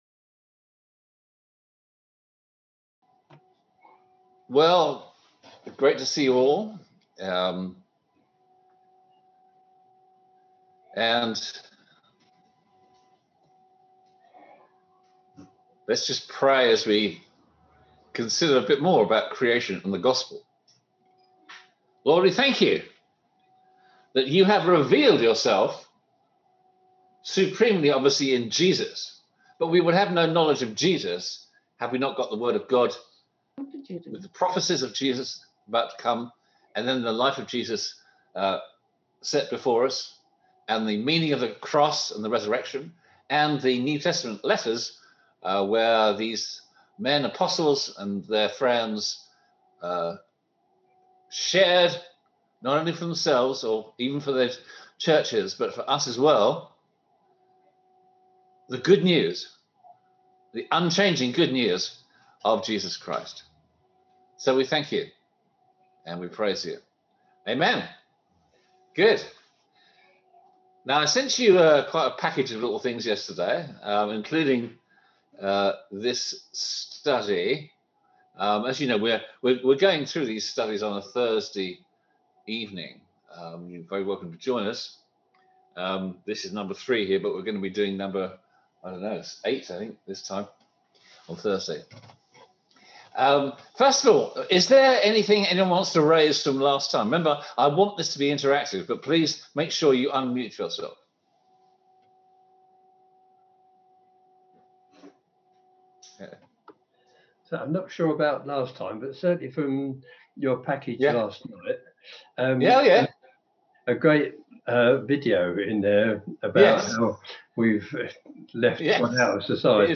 Service Type: On-Line Sunday Service